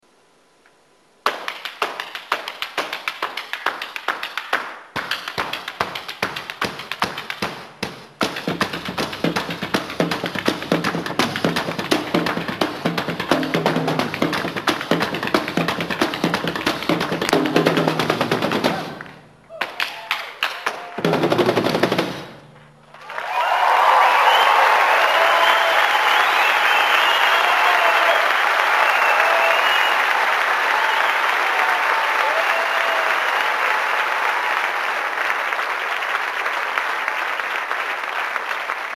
tango vokal
tolkala, bobni
klasična tango glasba
Bandoneón
klavir
violina
violončelo
kontrabas